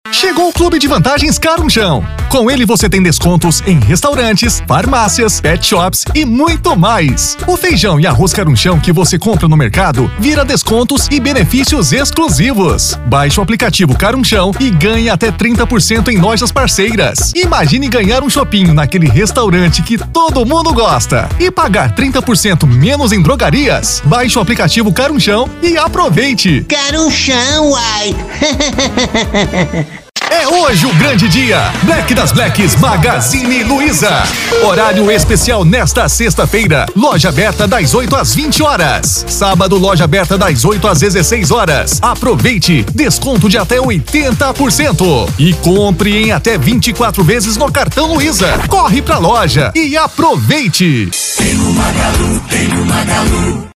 Spot Comercial
Vinhetas
Padrão
Animada